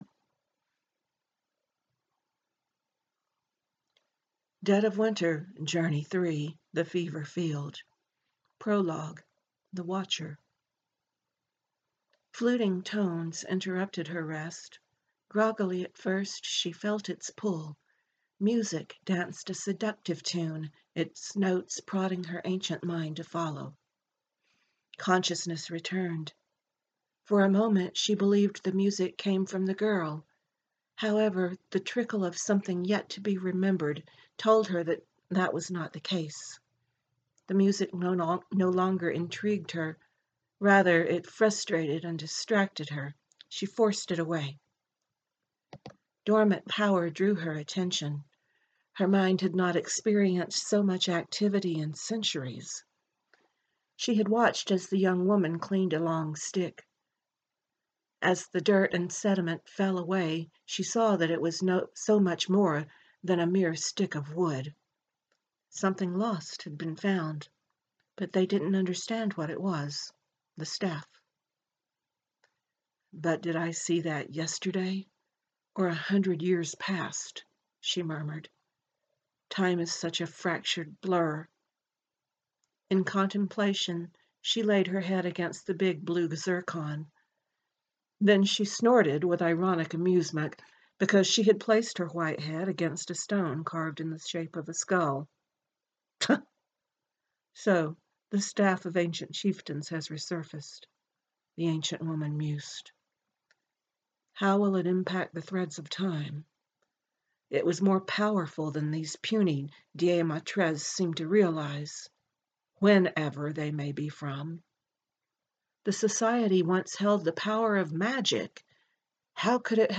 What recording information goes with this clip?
It’s an extremely casual recording, filled with flaws, but it’s my way of spending five minutes of personal time with all of you.